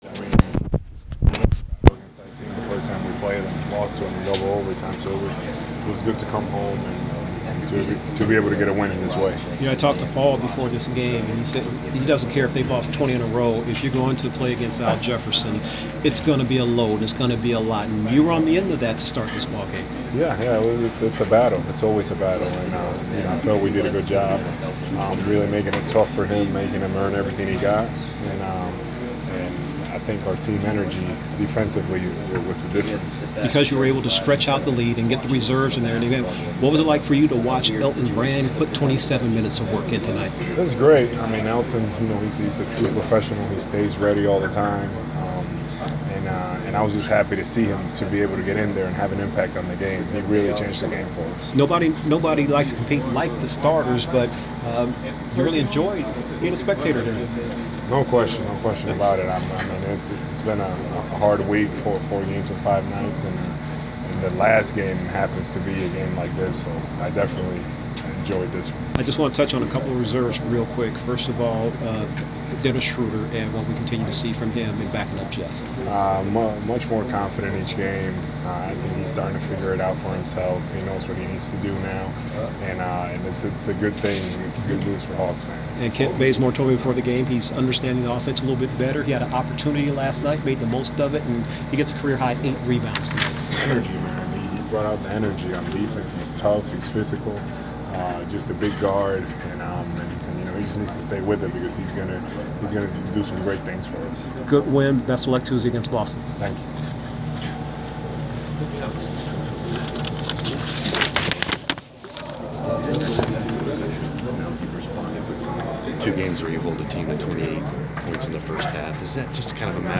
Inside the Inquirer: Postgame interview with Atlanta Hawks’ Al Horford (11/29/14)